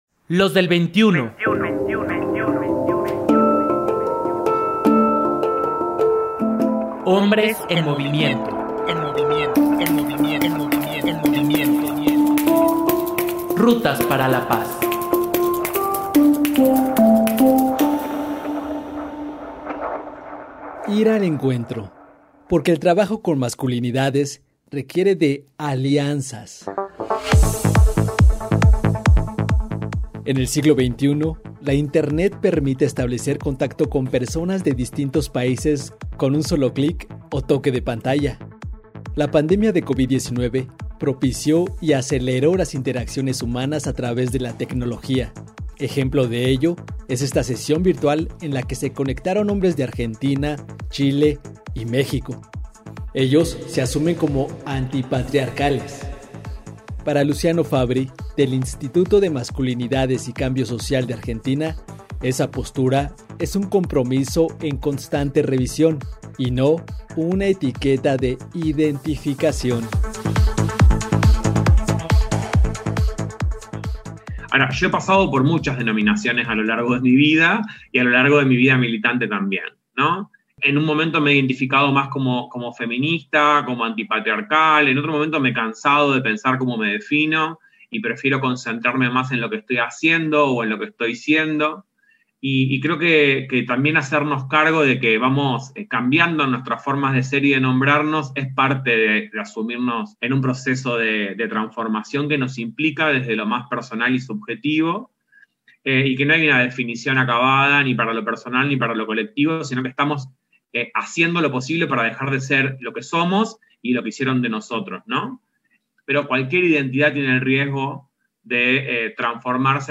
La pandemia de Covid-19 propició y aceleró las interacciones humanas a través de la tecnología, ejemplo de ello es esta sesión virtual en la que se conectaron hombres de Argentina, Chile y México.
Esta mesa fue organizada por la Unidad de Igualdad de Género de la UNAM.